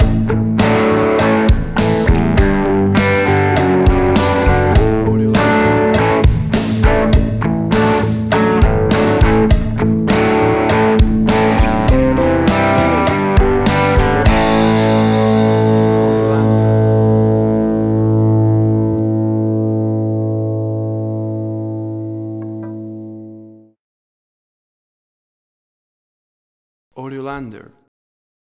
Classic Deep South Americana Style rock
WAV Sample Rate: 16-Bit stereo, 44.1 kHz
Tempo (BPM): 102